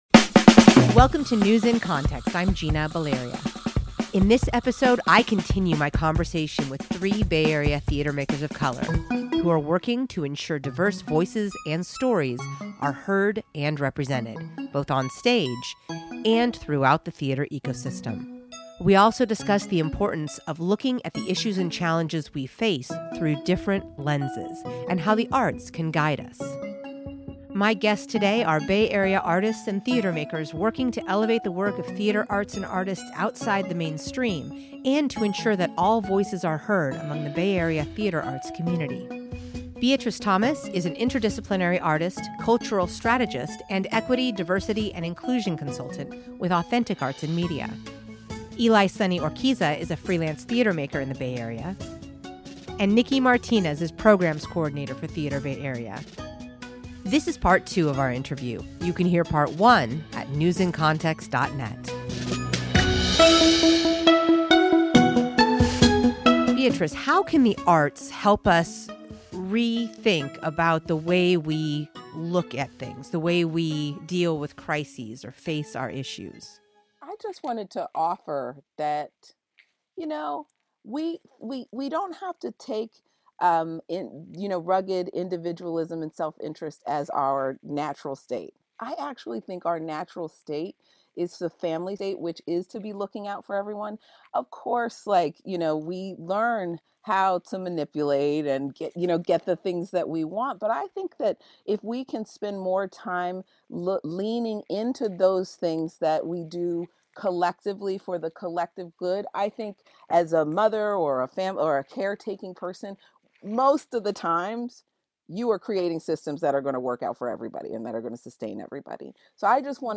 This is Part 2 of our interview.
In this episode, I continue my conversation with three Bay Area theatre makers of color who are working to ensure diverse voices and stories are heard and represented… both on stage… and throughout the threatre ecosystem. We also discuss the importance of looking at the issues and challenges we face through different lenses… and how the arts can guide us.